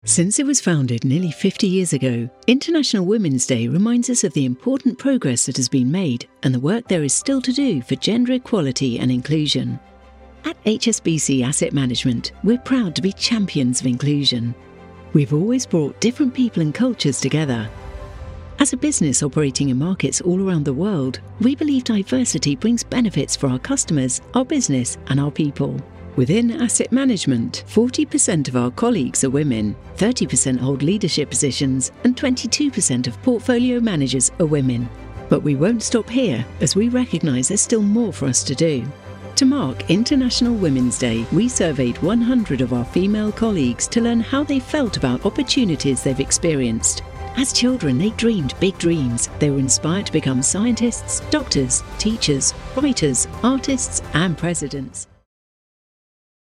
British English Female Voice Over Artist
Assured, Authoritative, Confident, Conversational, Corporate, Deep, Engaging, Friendly, Funny, Gravitas, Natural, Posh, Reassuring, Smooth, Upbeat, Versatile, Warm
Microphone: Neumann TLM 103
Audio equipment: Focusrite clarett 2 PRE, Mac, fully sound-proofed home studio